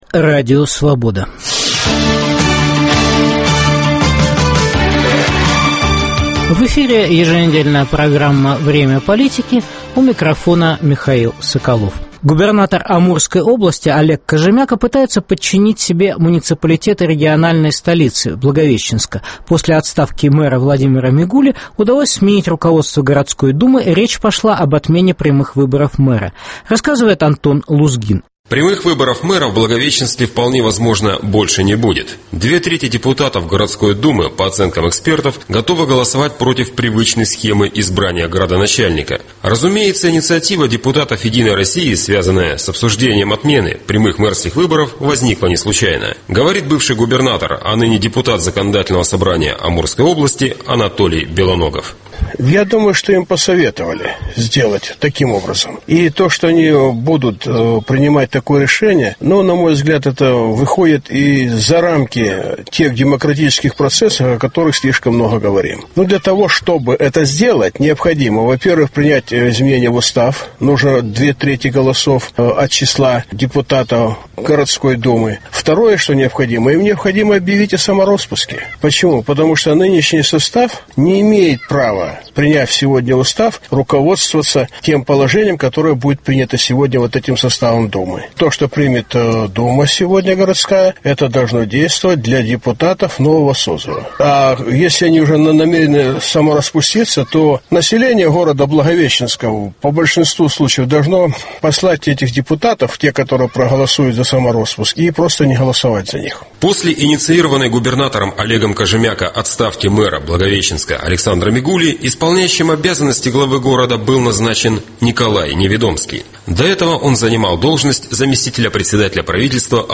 Борьба за пост мэра Самары. Специальный репортаж